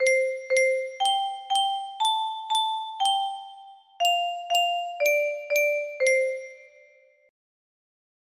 music box melody